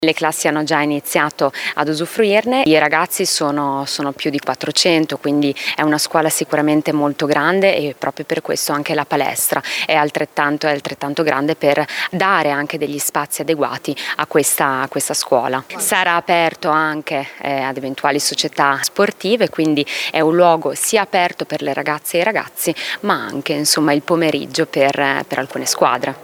L’assessore alla scuola Federica Venturelli: